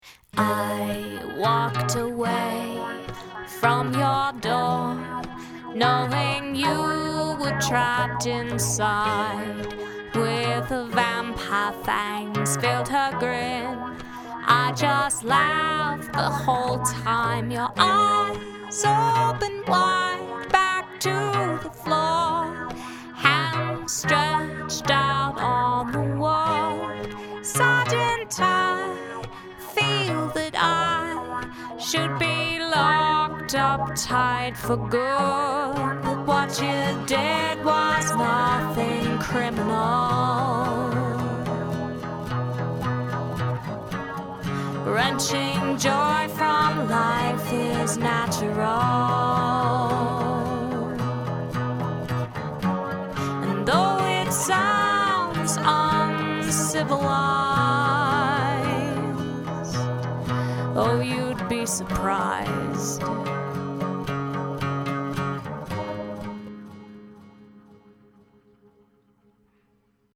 Interesting inunciation of some words.
It's mainly the vocal that carries this.